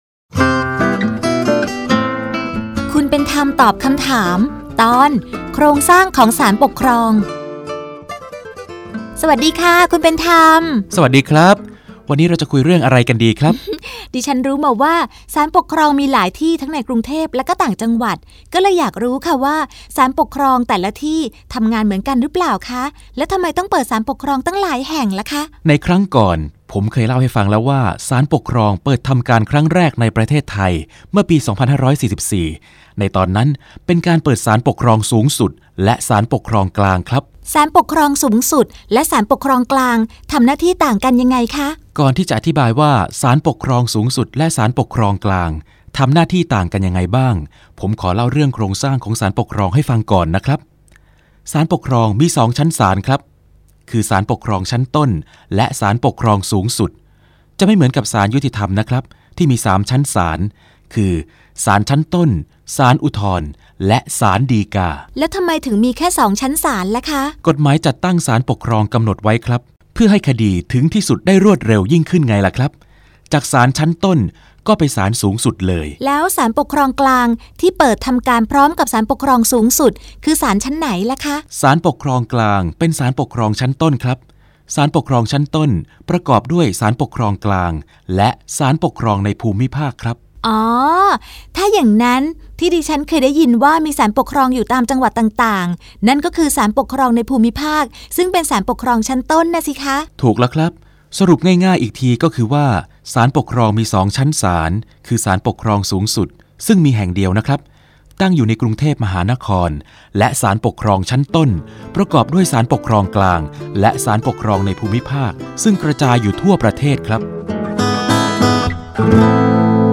สารคดีวิทยุ ชุดคุณเป็นธรรมตอบคำถาม ตอน โครงสร้างของศาลปกครอง
ลักษณะของสื่อ :   คลิปการเรียนรู้, คลิปเสียง